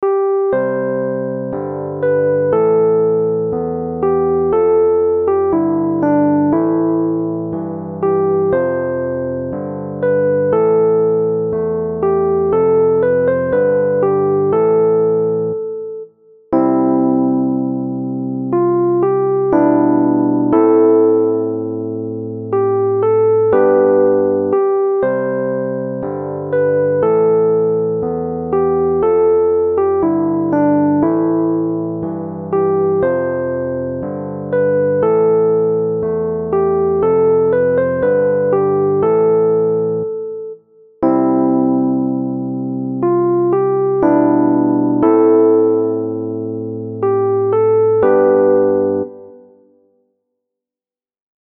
Sung together with a tone for the verses, music (quite close in harmonic structure to Pachelbel’s Canon) and message are both strong.